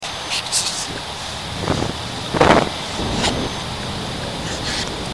It sounds like there's some backwards words in there, amongst a lot of static and breathing noises.